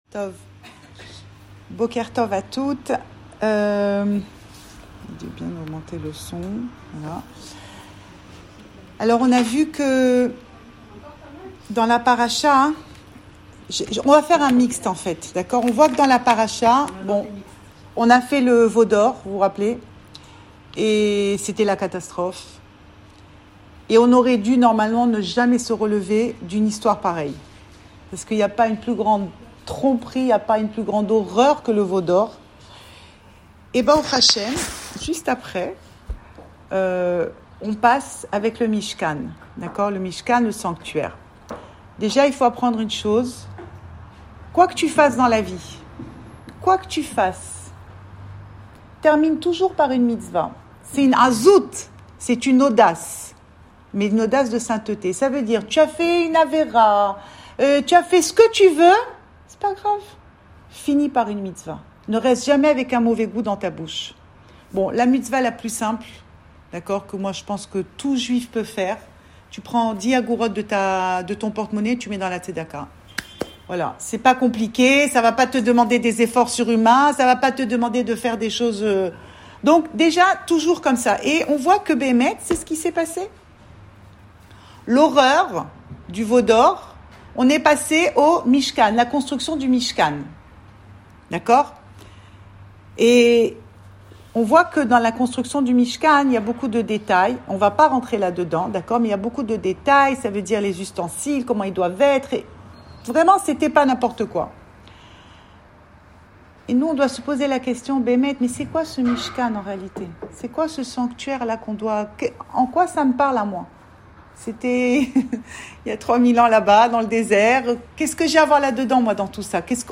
Parachat Vayaqhel Cours audio Le coin des femmes Pensée Breslev
Enregistré à Tel Aviv